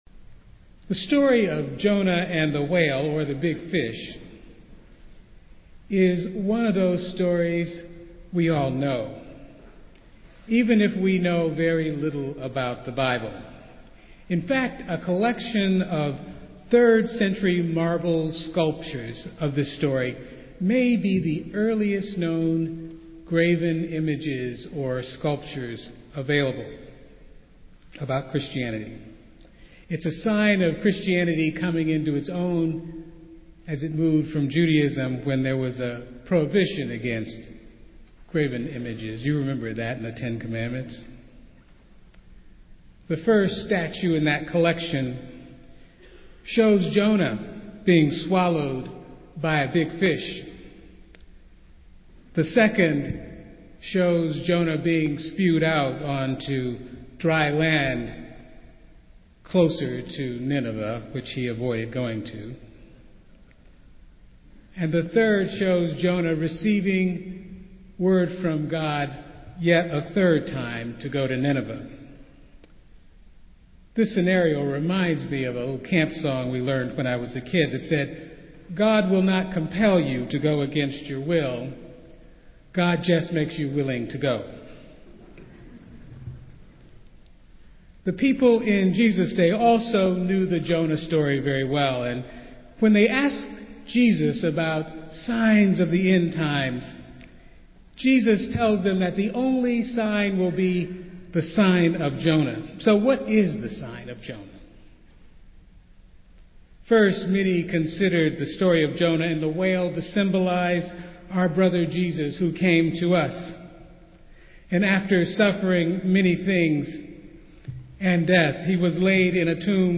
Festival Worship - Third Sunday of Easter